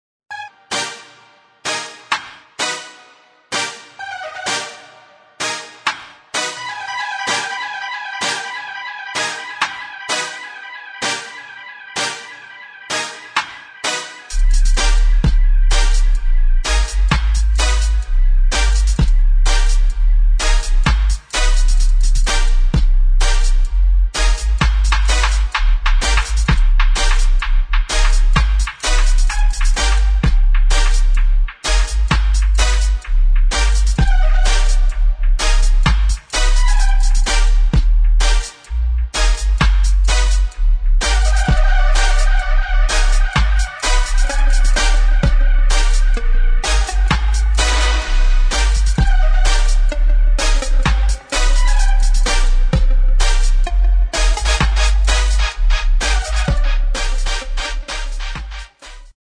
[ DUB | REGGAE ]